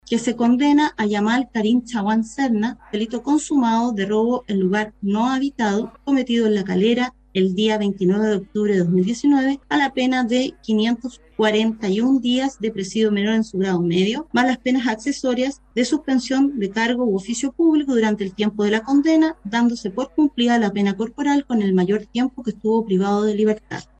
La magistrada Mónica Oliva fue la encargada de dar la lectura de sentencia en esta causa.